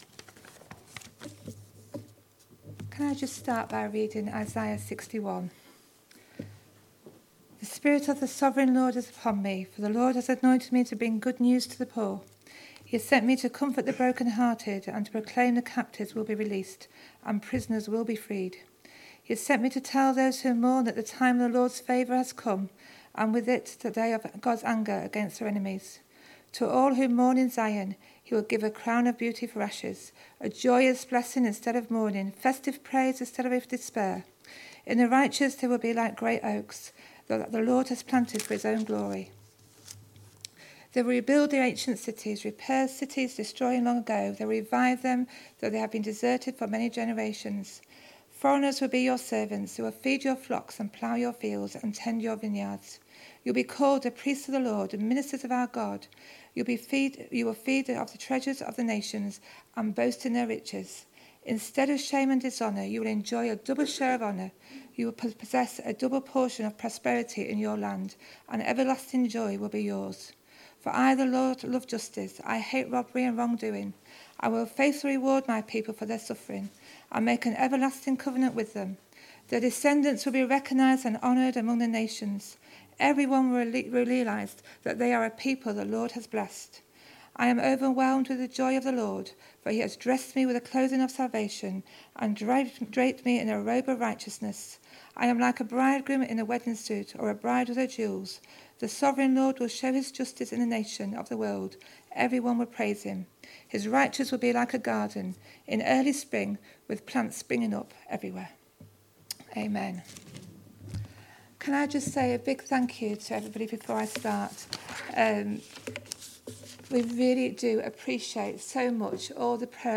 Testimony – Rock Foundation